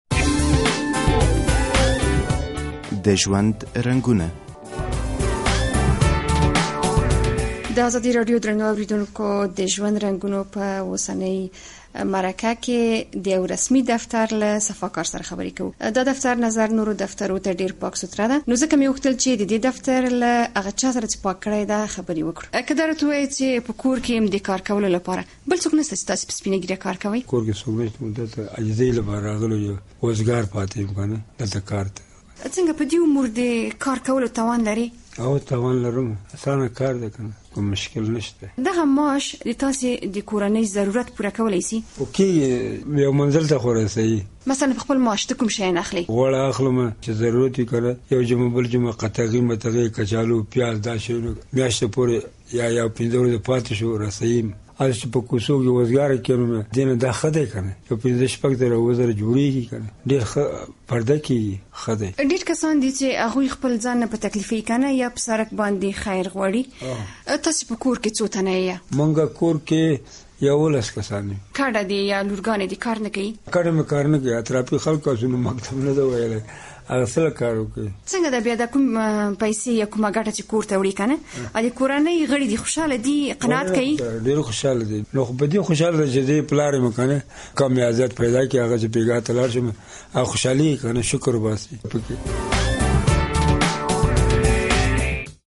په یوه رسمي دفتر کې مو دا ځیل له یوه صفاکار سره مرکه کړې نوموړی له عمر نه ډیر پوښ دی خو همت يي نه دی بایللی او د ژوند هره ستونزې ته يي اوږه ورکړې.